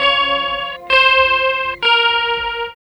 60 GUIT 1 -L.wav